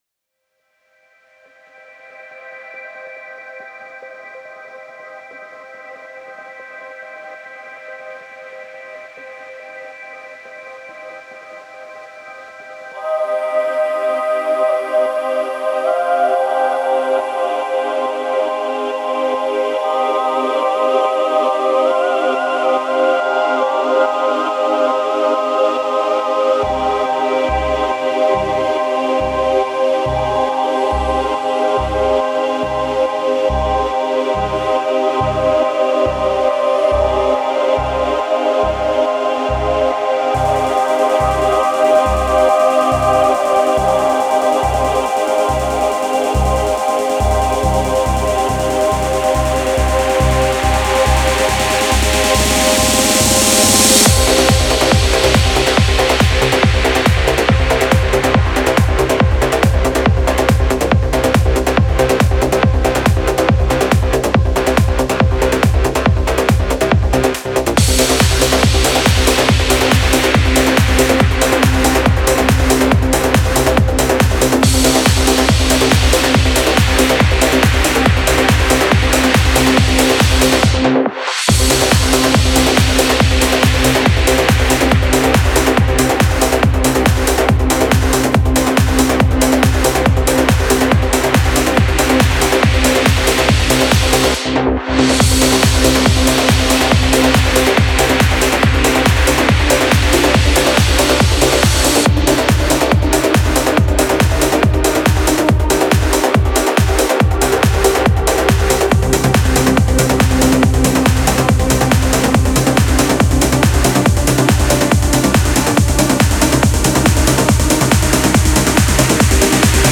سبک پر‌انرژی , ترنس , موسیقی بی کلام